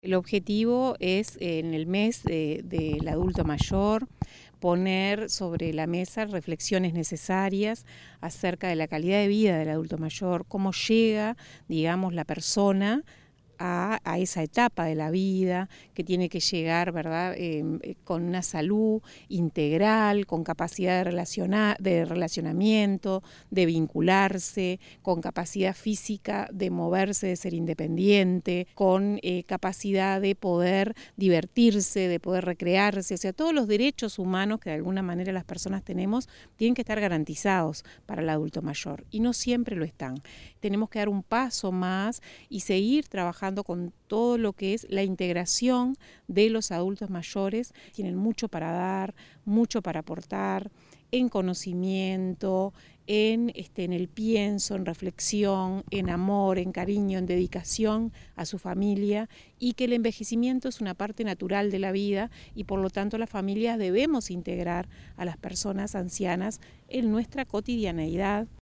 La Directora General de Desarrollo Humano de la Intendencia de Canelones, Prof. Gabriela Garrido, comentó que la finalidad de las jornadas fue “poner sobre la mesa reflexiones necesarias acerca de la calidad de vida del adulto mayor”.